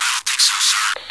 radio_no3.wav